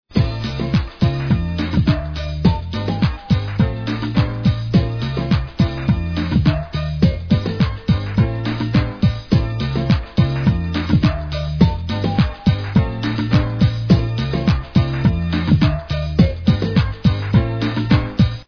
Garage, House and Hip Hop tracks